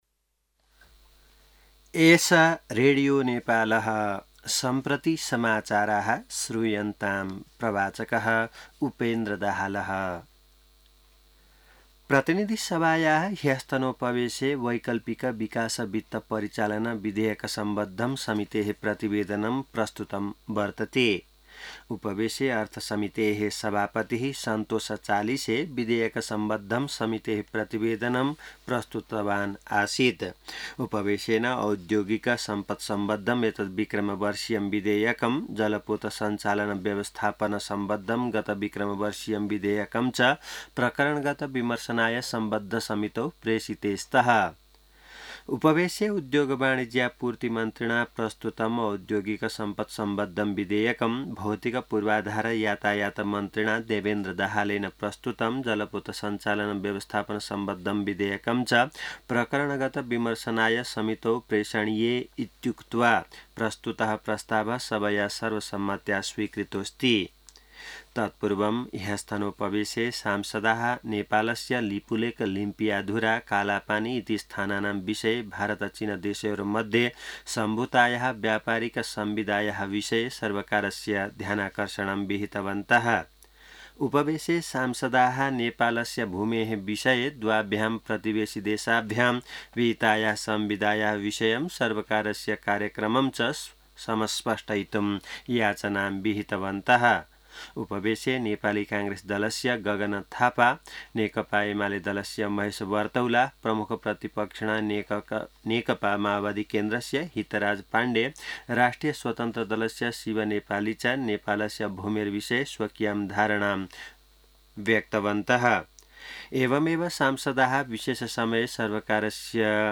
संस्कृत समाचार : ६ भदौ , २०८२